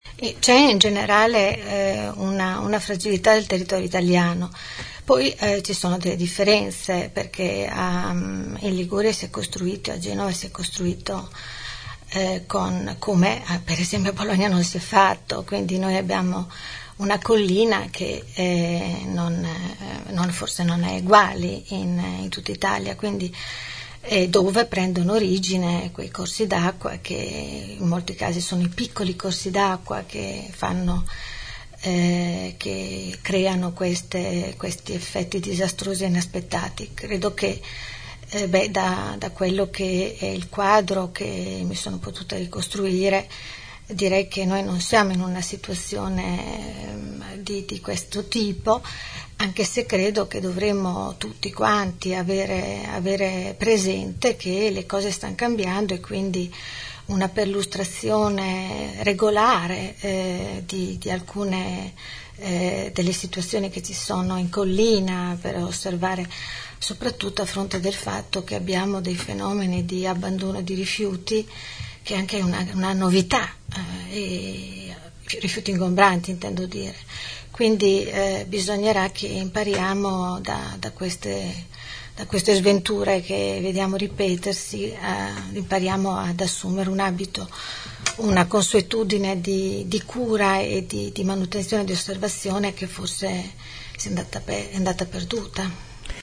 5 nov. – Patrizia Gabellini, assessore all‘urbanistica e all’ambiente, ospite questa mattina nei nostri studi, descrive così il carattere di Bologna: “è di una lentezza spaventosa, discute tanto e fa fatica a convergere, è molto attaccata al passato e ha paura“.